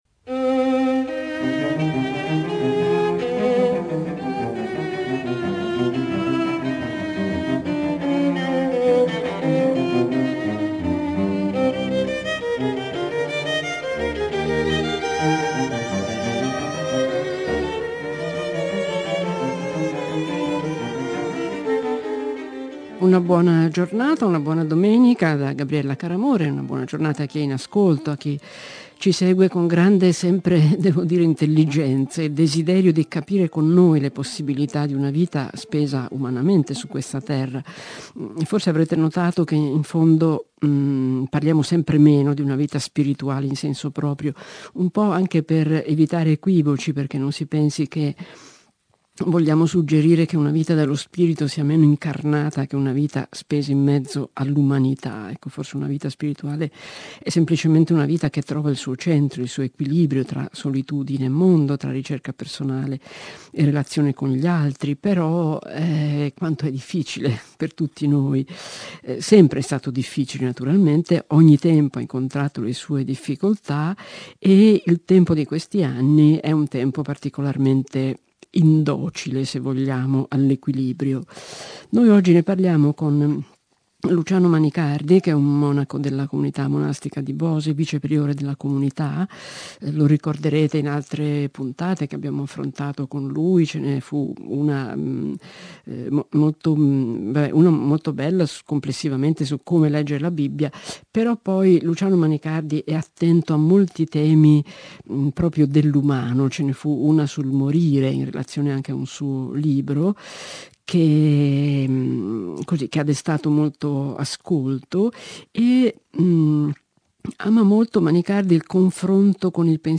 da "uomini e profeti" trasmissione di radio tre.